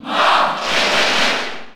Category:Marth (SSB4) Category:Crowd cheers (SSB4) You cannot overwrite this file.
Marth_Cheer_French_NTSC_SSB4.ogg